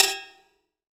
6TIMBALE H1O.wav